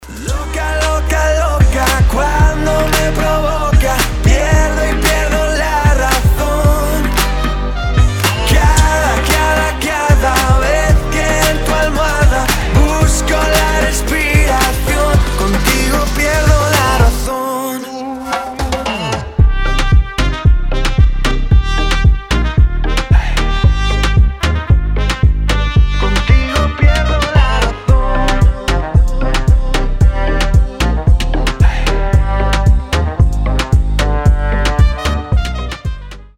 • Качество: 320, Stereo
мужской вокал
труба
Latin Pop